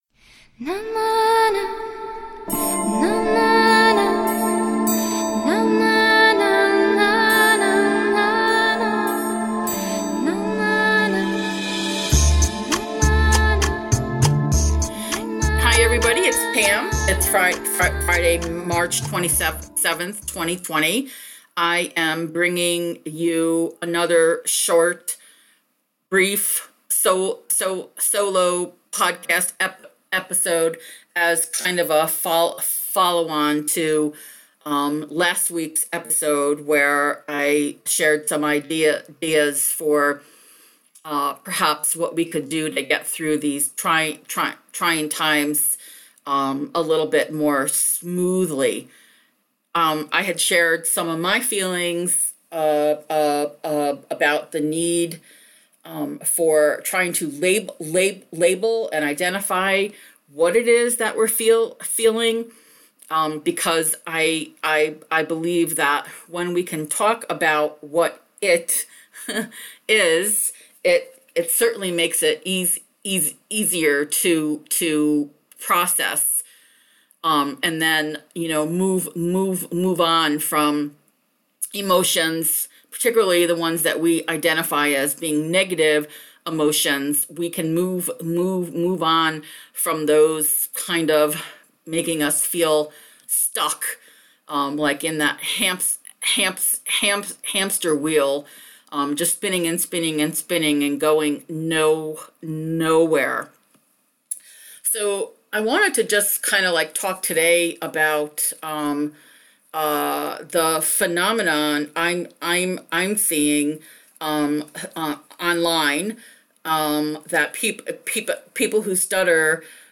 Today I bring another short episode, solo, talking about identifying feelings and the grief that many of us feel, but don’t rightly recognize as grief.